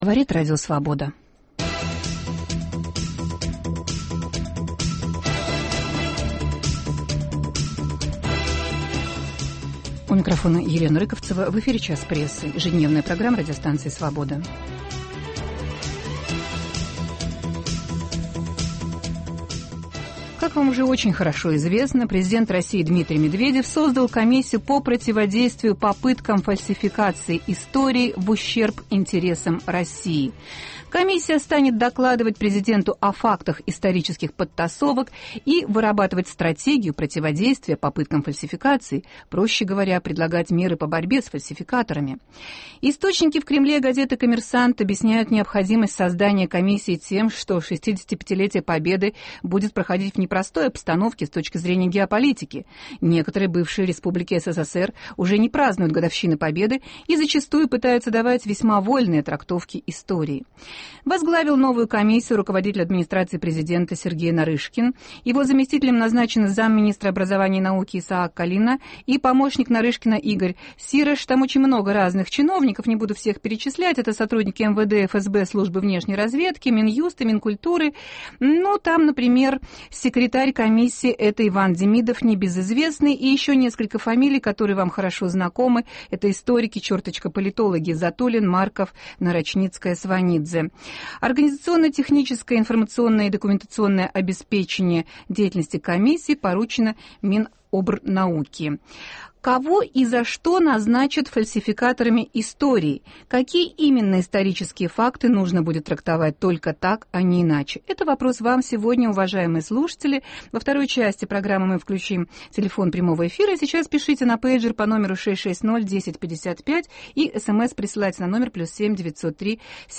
Журналист "МК" Александр Минкин и писатель Виктор Суворов обсуждают новые методы защиты отечественной истории, предложенные президентом РФ.